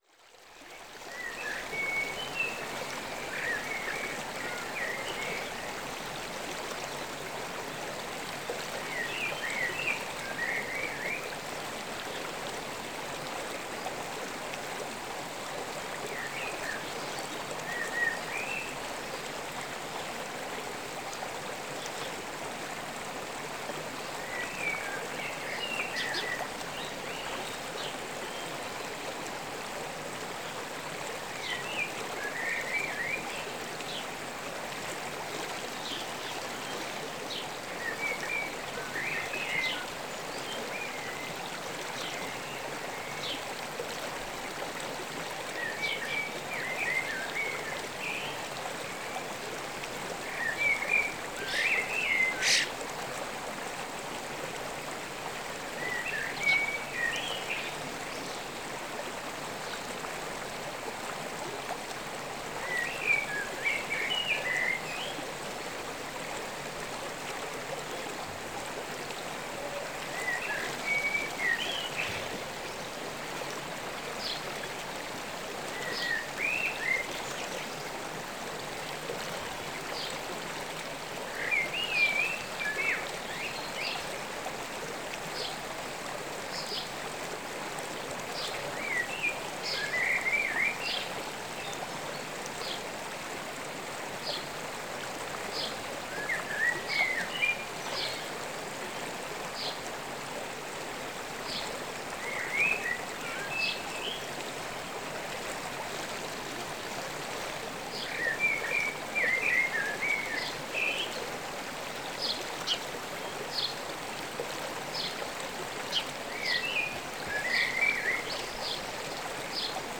Soft River Sounds With Birds | Free MP3 Download
This audio was recorded deep in the highlands of Scotland where a soft flowing river had, over millennia, carved it's way through the mountain rock.
Title: Soft River Sounds With Birds
river-sounds-with-birds-dollar-3-10m.mp3